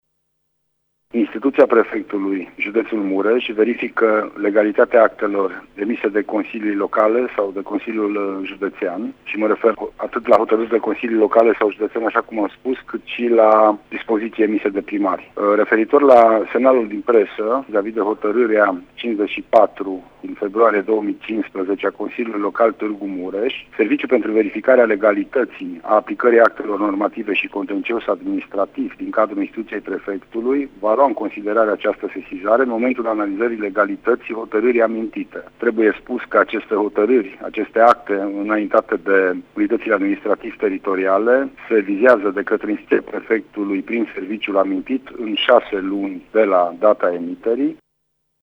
Prefectul judeţului Mureş, Lucian Goga, a declarat că instituţia va analiza hotărârea în cauză la fel cum face cu toate hotătârile consiliilor locale înainte de a da viza de legalitate: